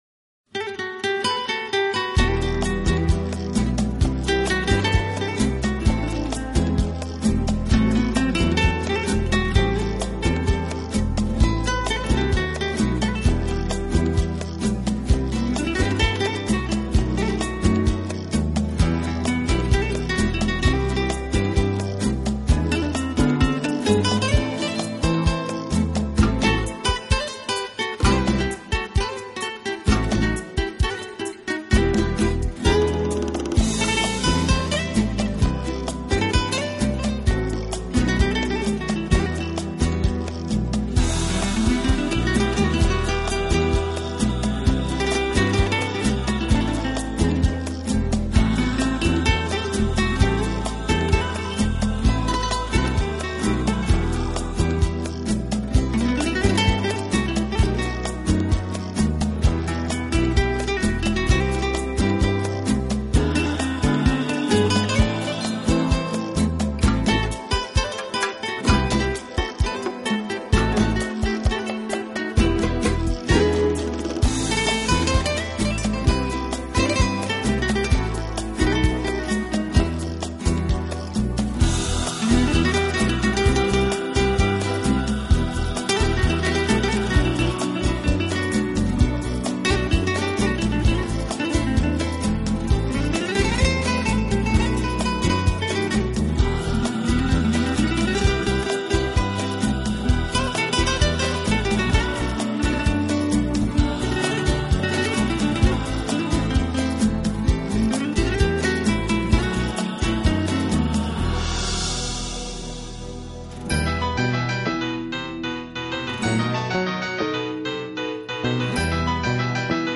专辑语言：纯音乐
而形成他独特的风格，在热情洋溢的佛朗明哥节奏中，仍然带着一些细腻的伤感